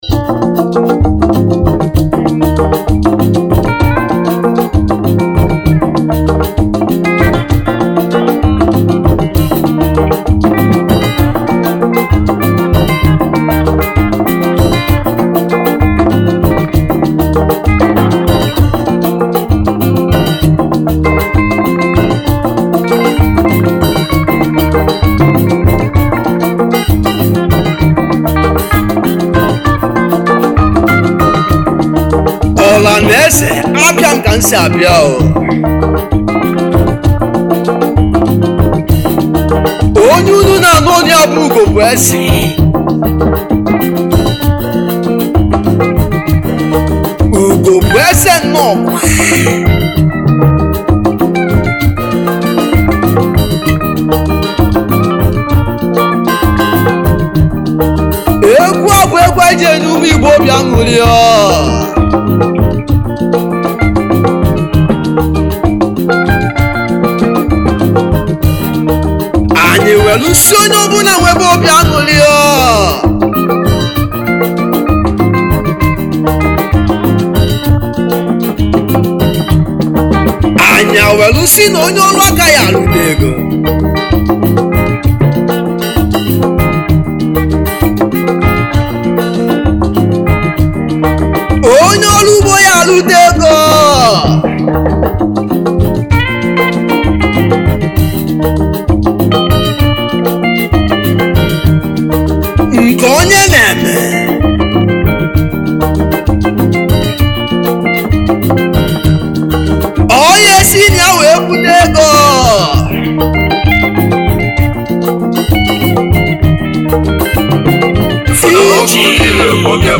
Category : Highlife
Highlife Traditional Free